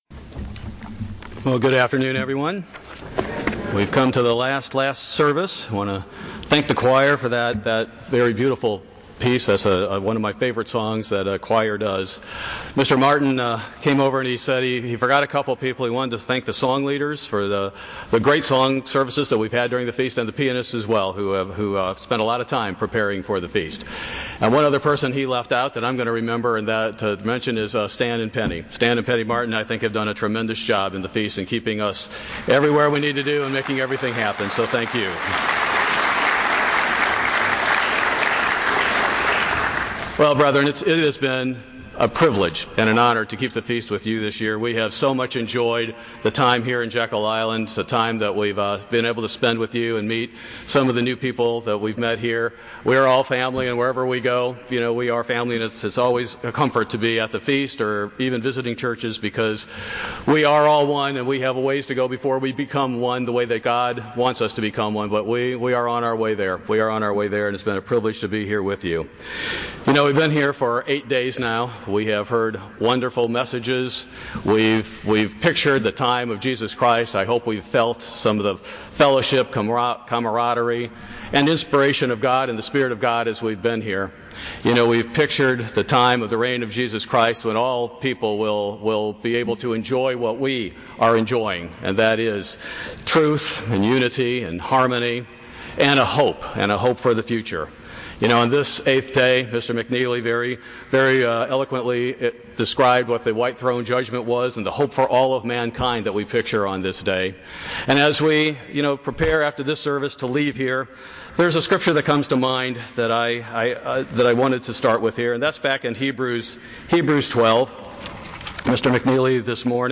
This sermon was given at the Jekyll Island, Georgia 2018 Feast site.